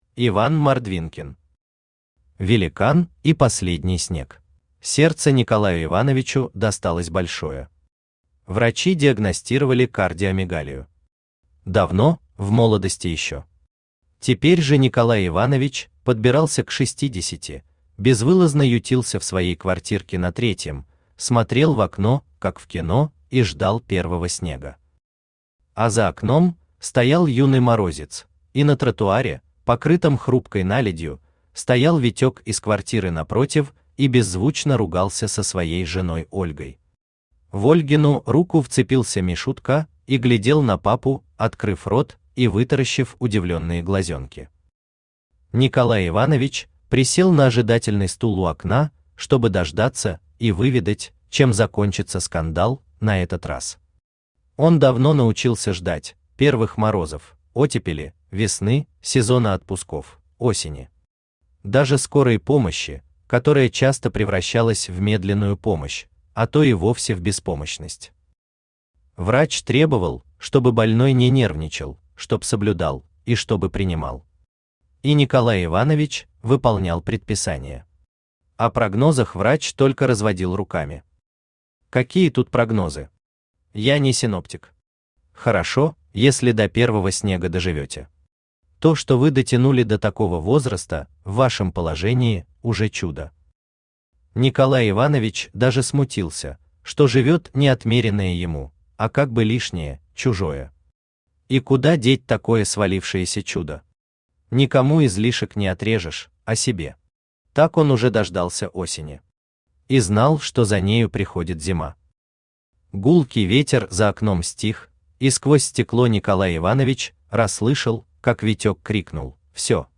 Аудиокнига Великан и последний снег | Библиотека аудиокниг
Aудиокнига Великан и последний снег Автор Иван Александрович Мордвинкин Читает аудиокнигу Авточтец ЛитРес.